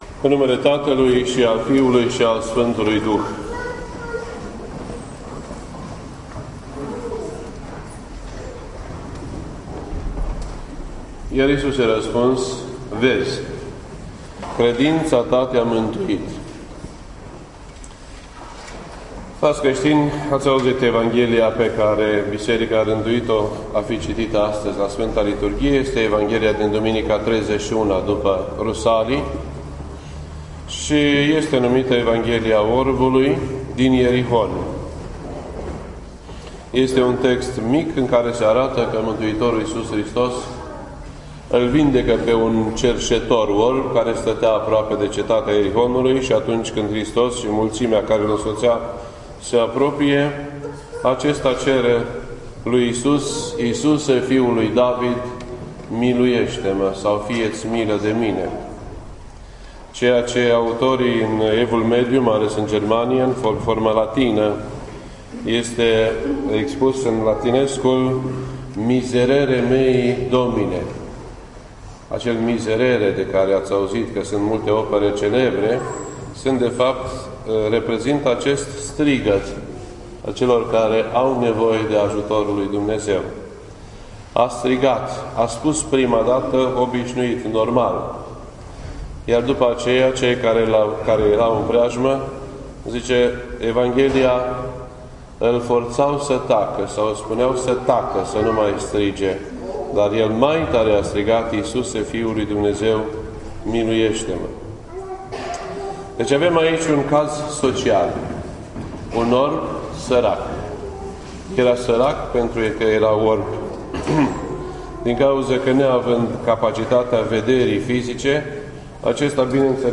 This entry was posted on Sunday, January 24th, 2016 at 6:24 PM and is filed under Predici ortodoxe in format audio.